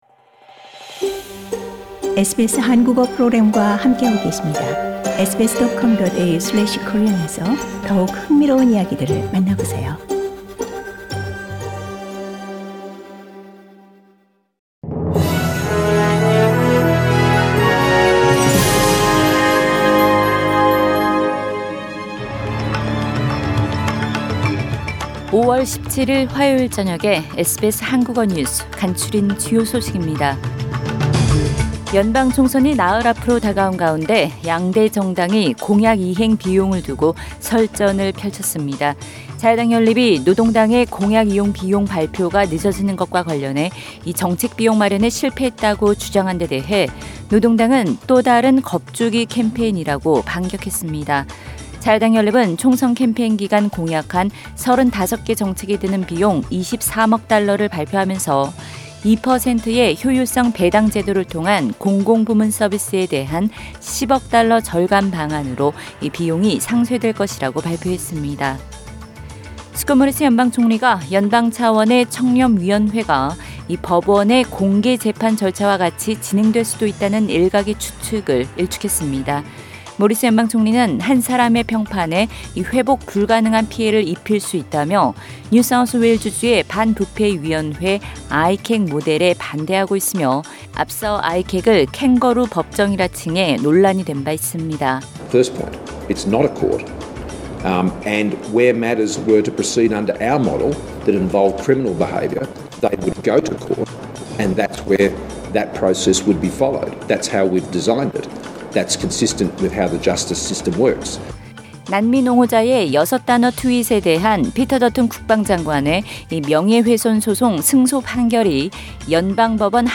2022년 5월 17일 화요일 저녁 SBS 한국어 간추린 주요 뉴스입니다.